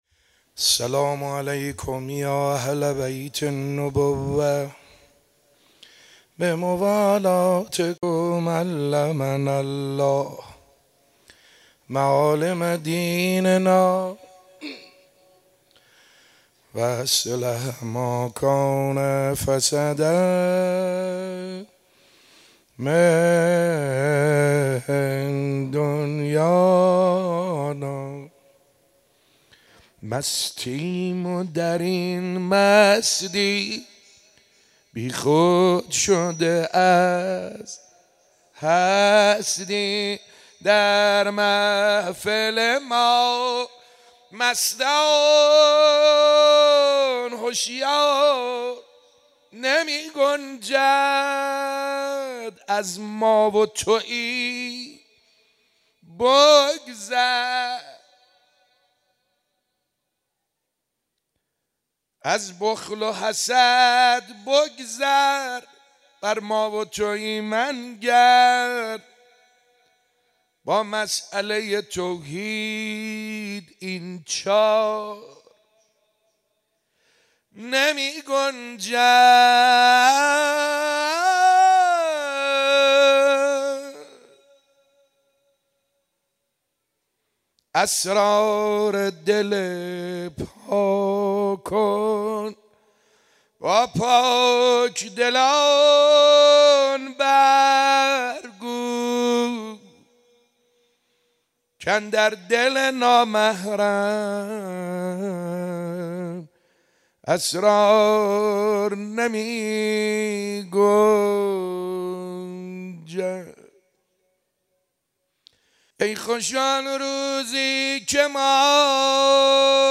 شب دوم فاطمیه در مسجد ارک
حاج سعید حدادیان- پیش منبر